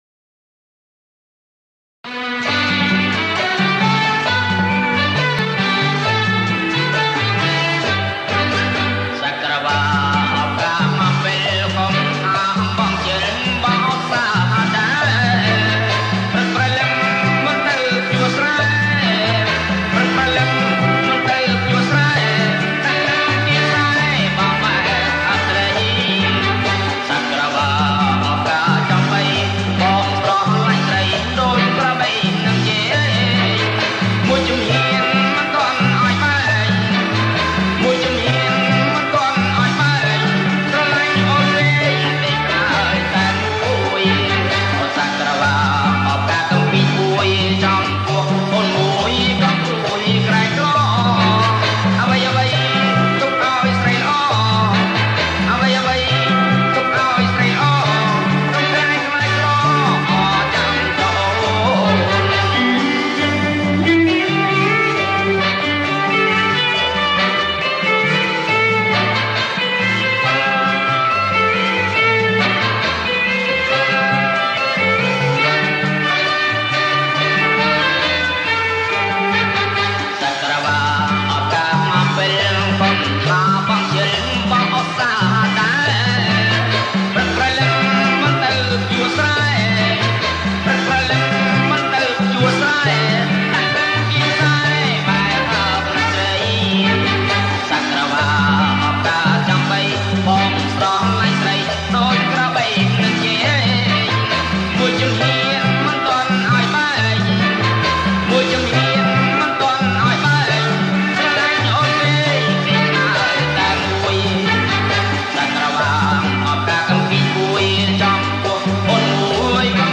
• ប្រគំជាចង្វាក់ រាំវង់
ប្រគំជាចង្វាក់ រាំវង់